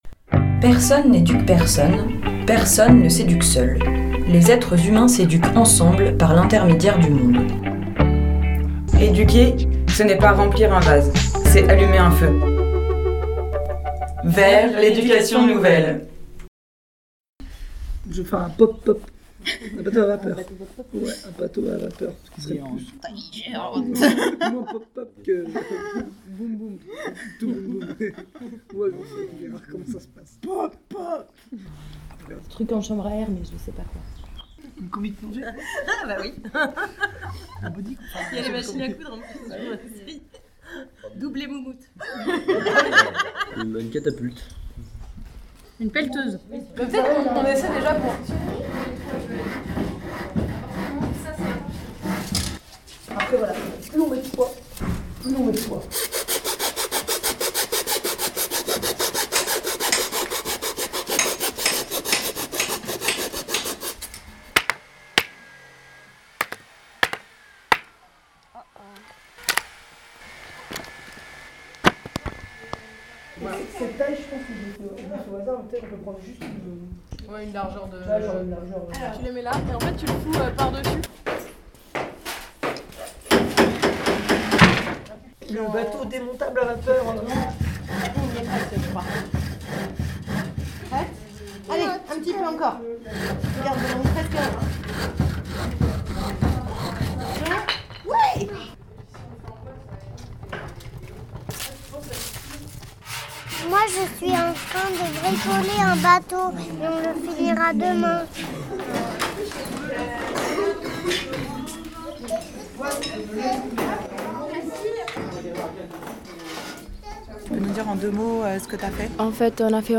Pour cette émission, nous nous sommes immergés au sein d’un regroupement animation des CEMEA et des Eclaireur.euse.s où les questions d’oppressions et dominations étaient travaillées
Lors de celui-ci une radio éphémère avait été mise en place et c’est donc à partir des différentes émissions produites lors de ce regroupement que nous avons construit cette émission du mois de janvier afin de vous immerger dans ces réflexions, ces échanges, son ambiance.